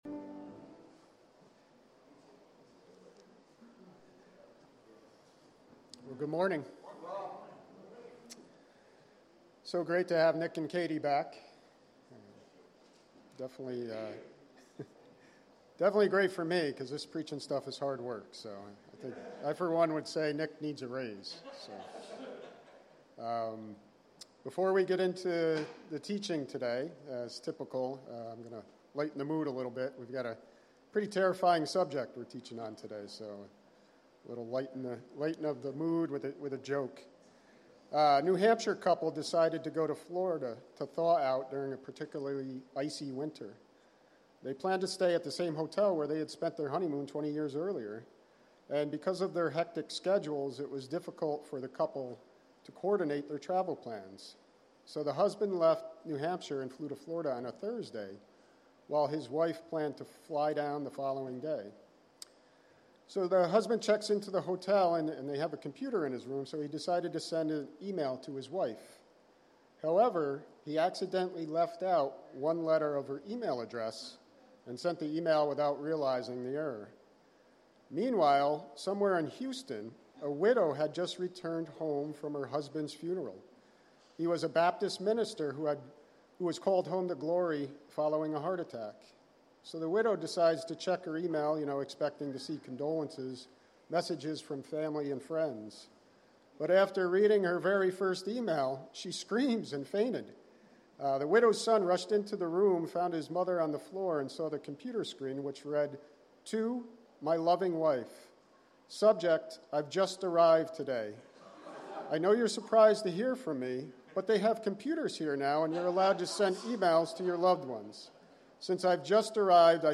Sermons by At the Cross